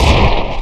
impact_01.wav